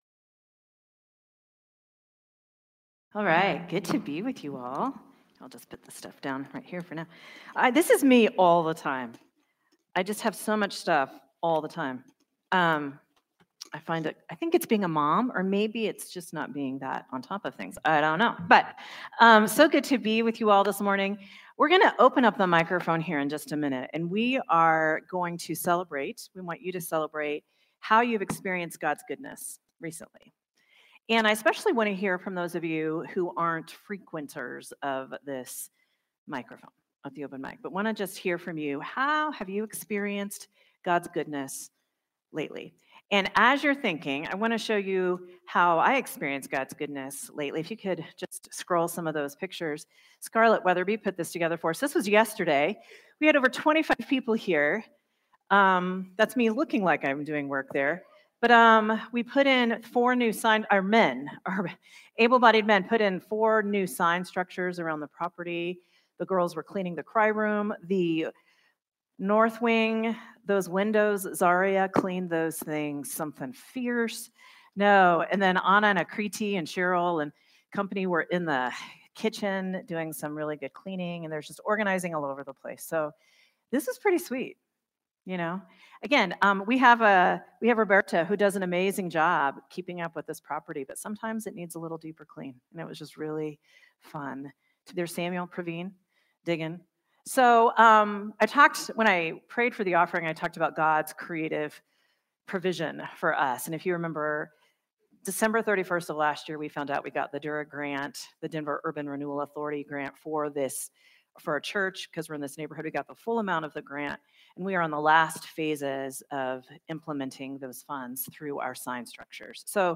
Sermon from Celebration Community Church on November 2, 2025